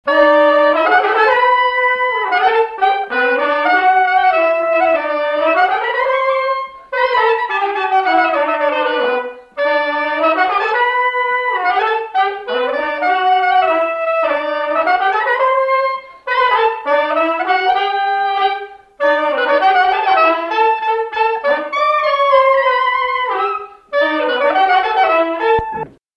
Marche nuptiale
Résumé instrumental
circonstance : fiançaille, noce
Pièce musicale inédite